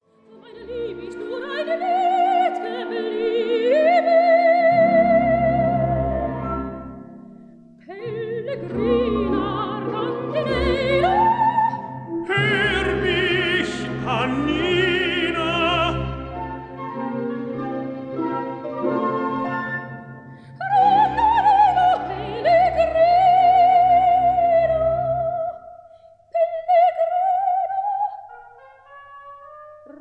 Stereo recording made in Berlin, July 1960